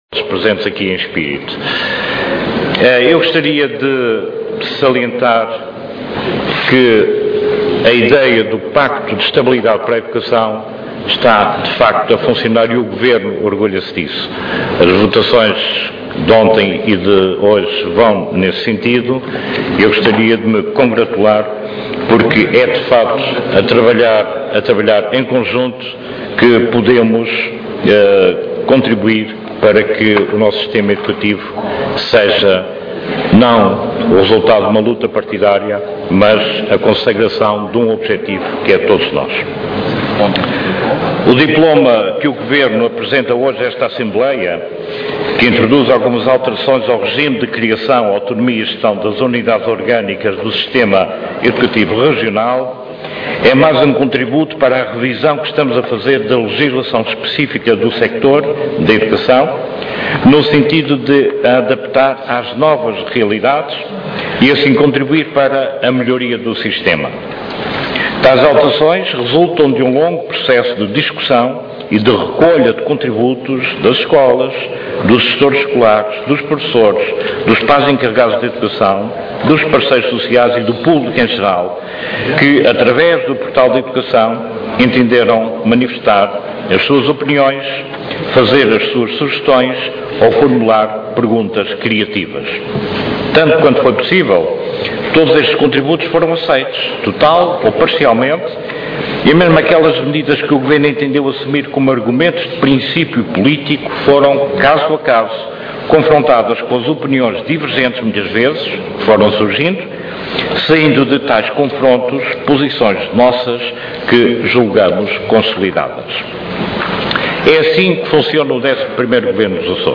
Intervenção do Secretário Regional da Educação, Ciência e Cultura